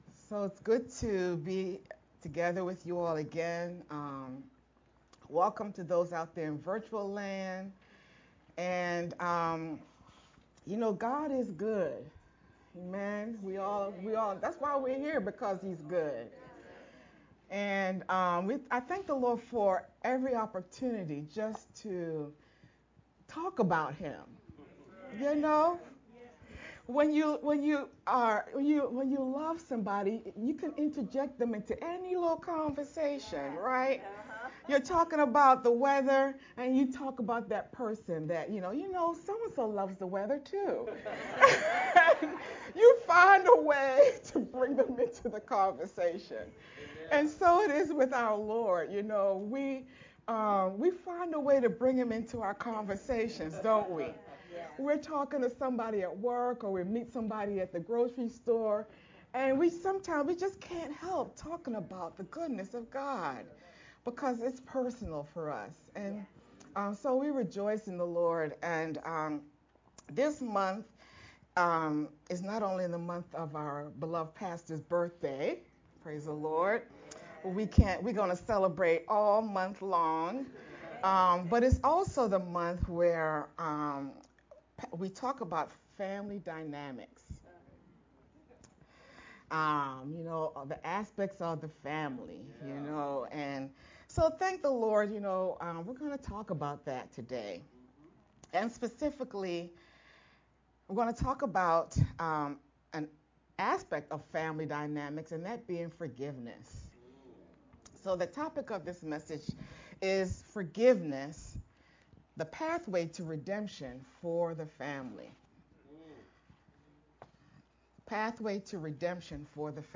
June-2nd-Sermon-only-VBCC-edited_Converted-1-CD.mp3